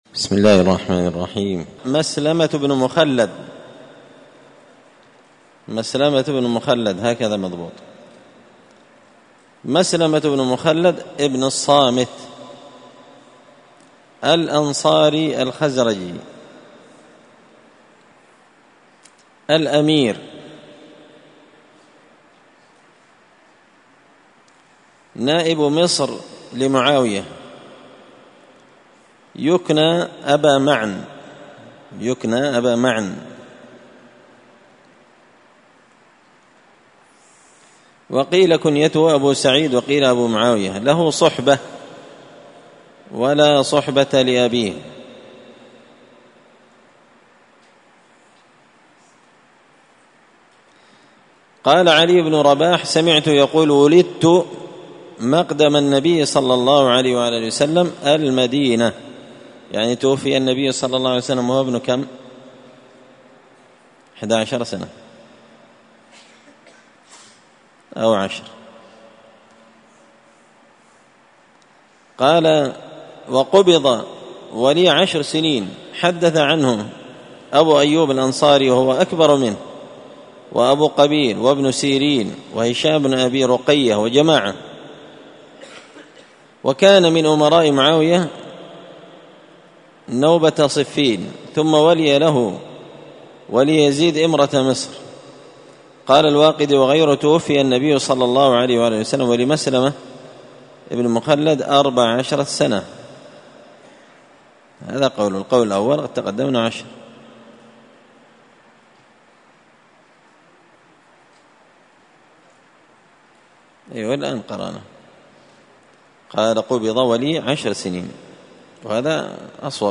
قراءة تراجم من تهذيب سير أعلام النبلاء
مسجد الفرقان قشن المهرة اليمن